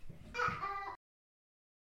Descarga de Sonidos mp3 Gratis: ohoh.
uh-oh-baby.mp3